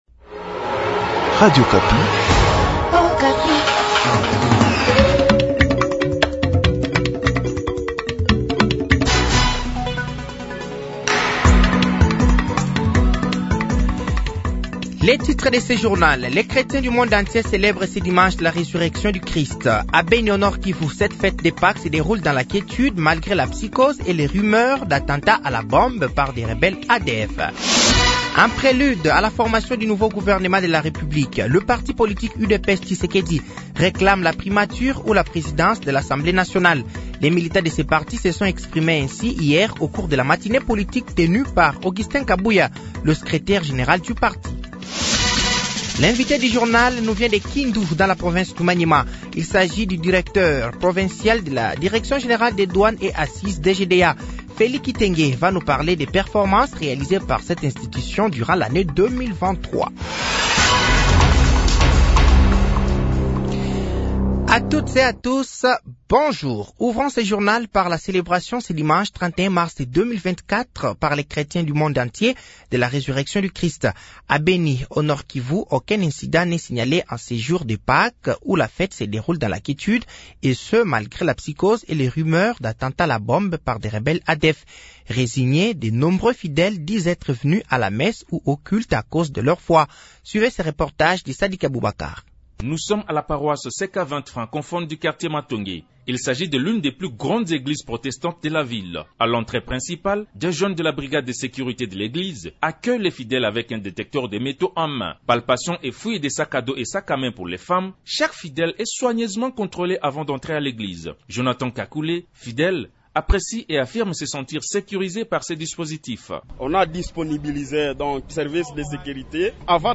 Journal français de 12h de ce dimanche 31 mars 2024